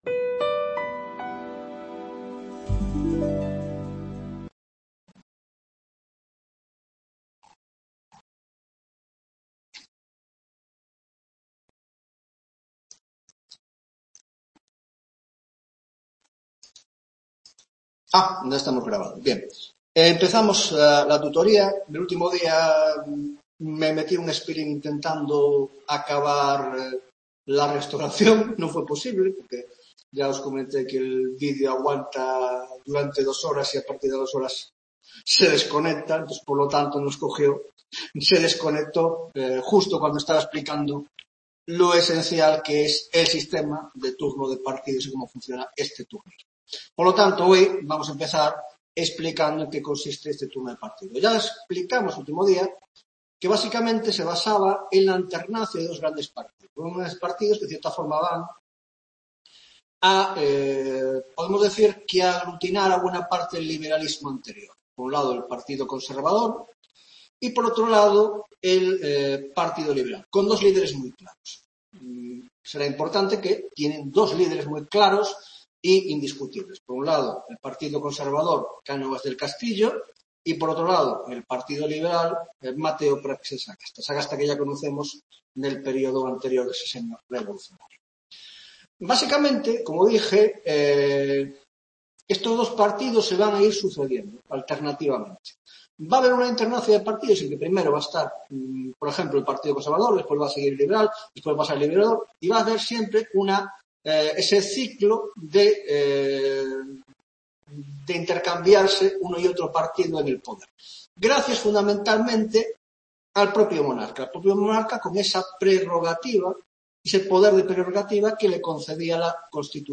10^¨ tutoría de Historia Contemporánea